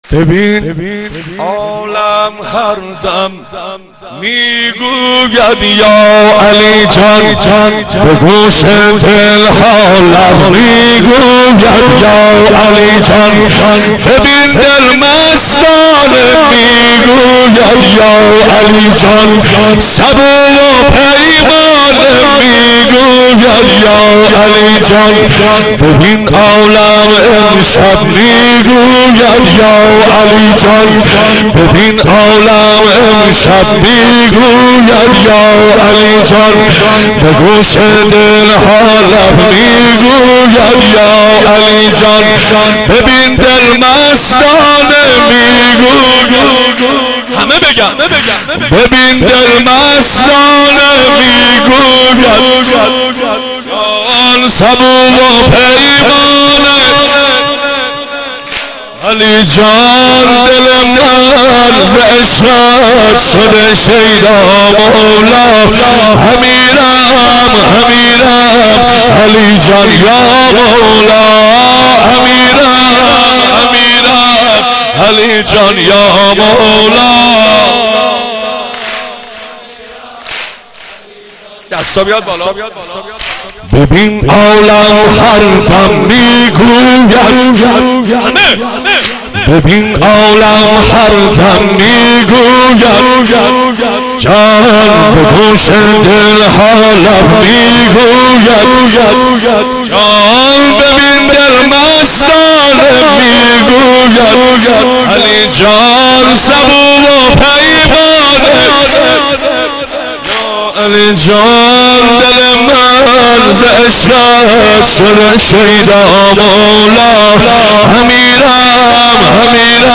مولودی | ببین عالم هر دَم می‌گوید یا علی جان
مولودی خوانی